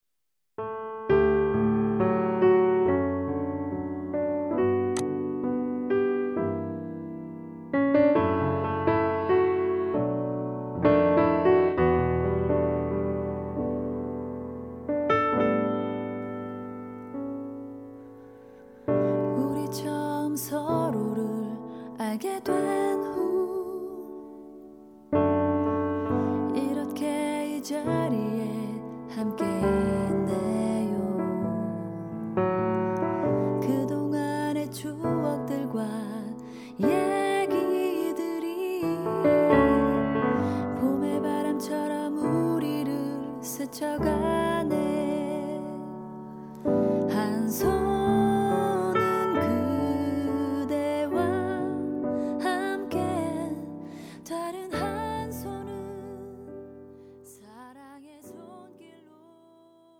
음정 원키
장르 가요 구분
가사 목소리 10프로 포함된 음원입니다